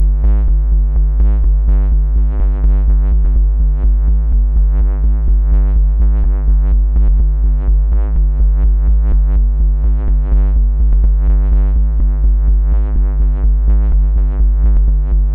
• Tech House Bass Rhythm Melody Sustained.wav
Loudest frequency 58 Hz
Tech_House_Bass_Rhythm_Melody_Sustained_mwI.wav